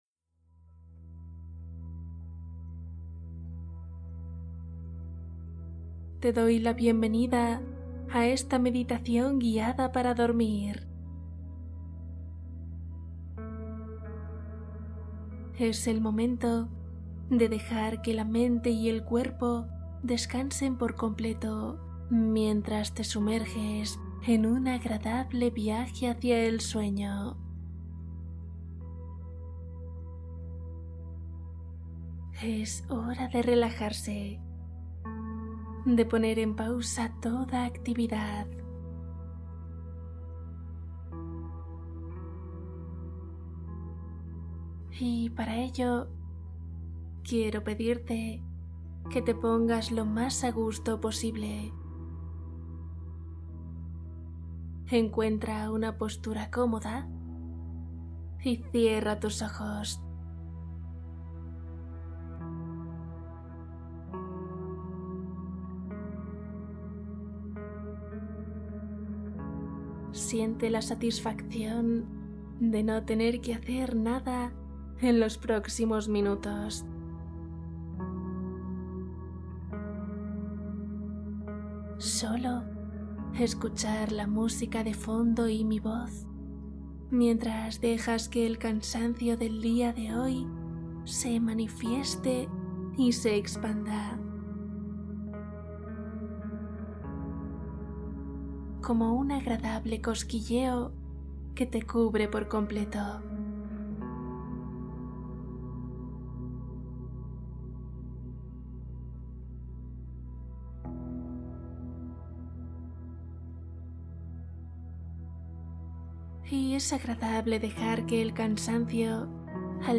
Relajación para dormir | Meditación para aliviar estrés y descansar feliz